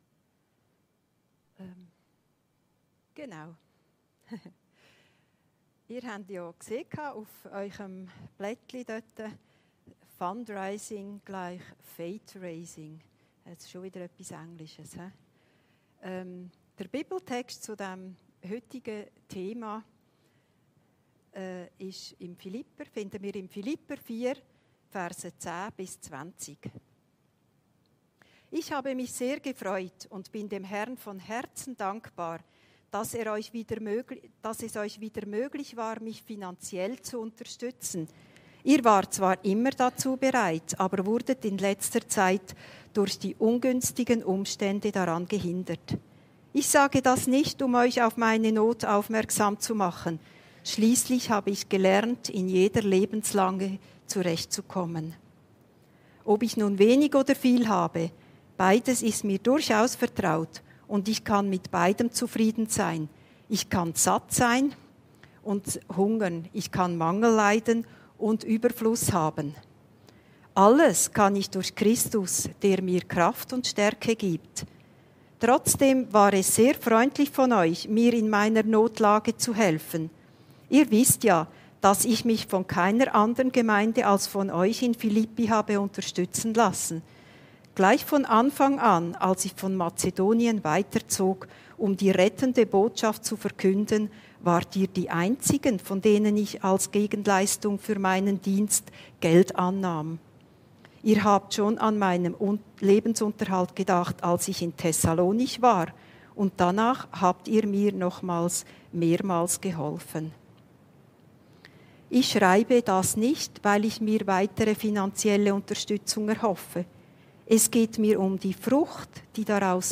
Predigten Heilsarmee Aargau Süd – FUNDRAISING=FATERAISING